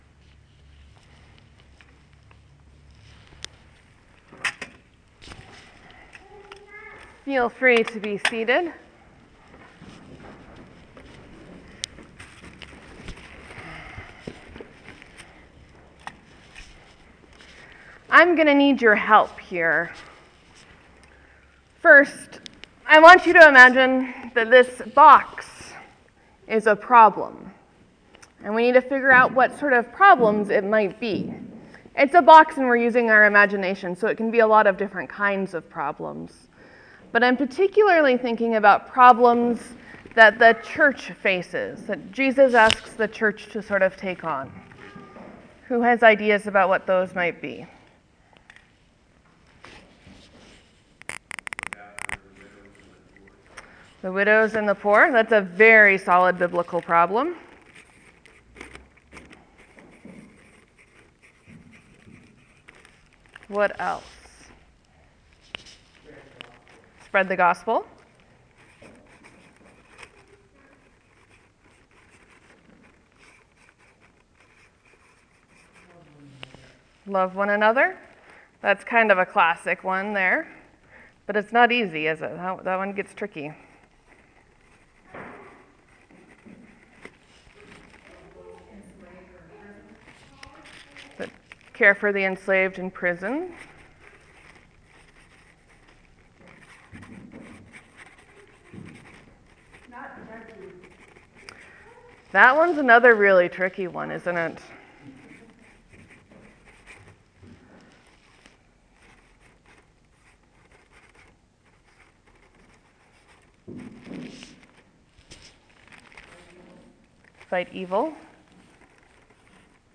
Today, for Morsels and Stories and the sermon, I talked a bit about why we’d do something like REACH and everyone present was invited to fill out a commitment card which we then placed on the altar and prayed over. (The audio stops once we start filling out the cards.)